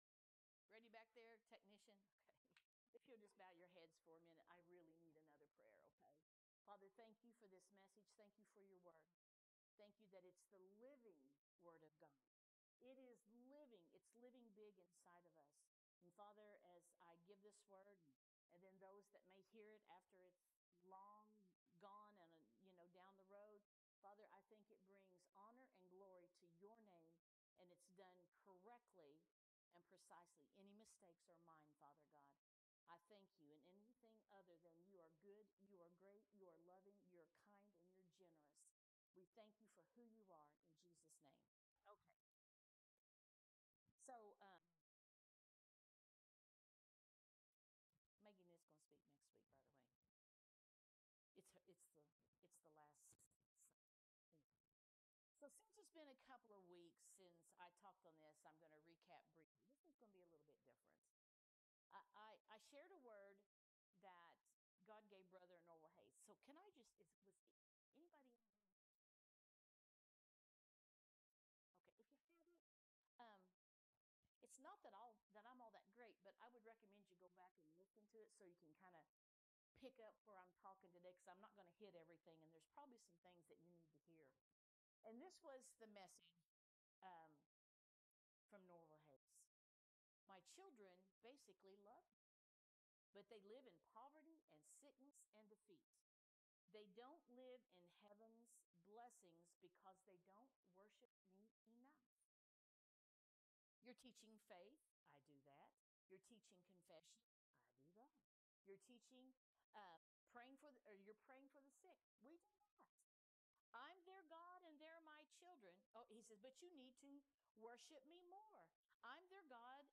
Praise and Worship pt2